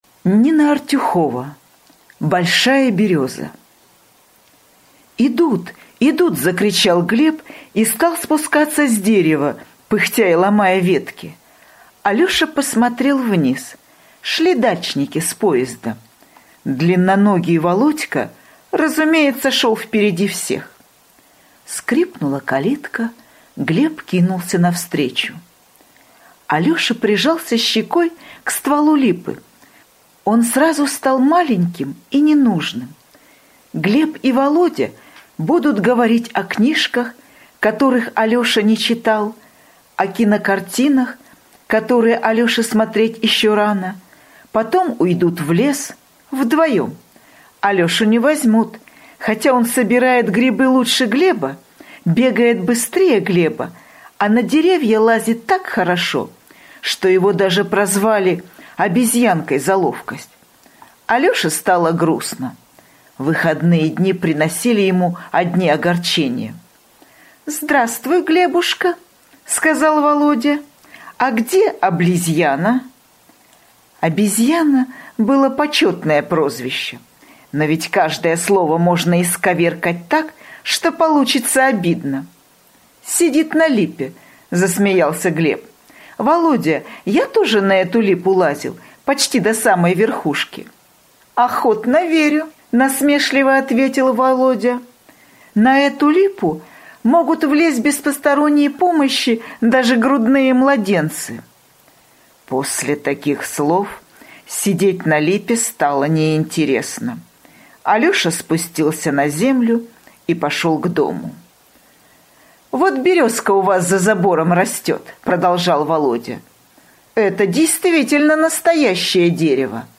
Большая береза — слушать аудиосказку Нина Артюхова бесплатно онлайн